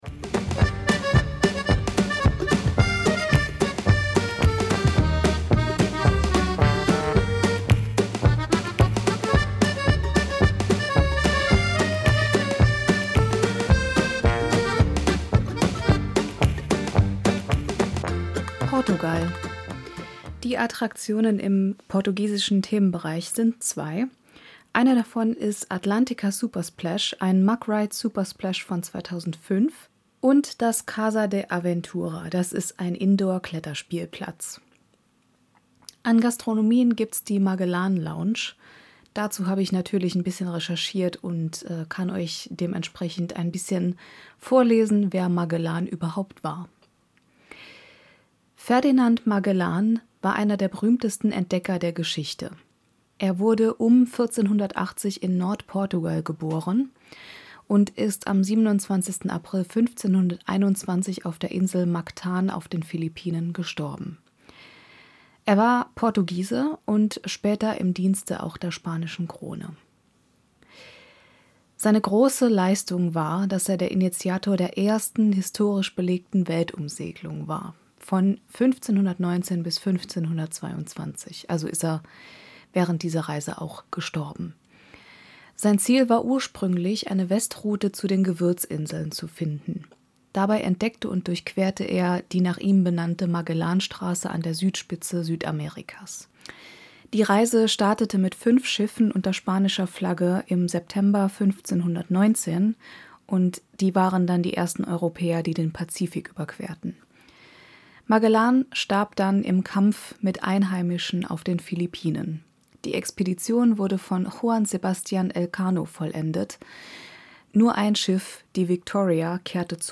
Music by Tunetank from Pixabay